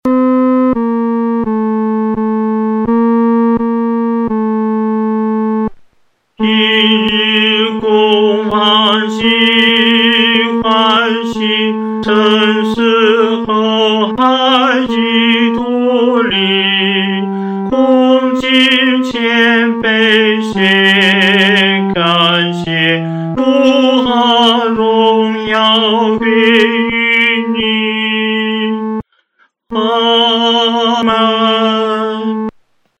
男高
它的进行简单朴素，所表达的是对神真诚的依赖与信靠。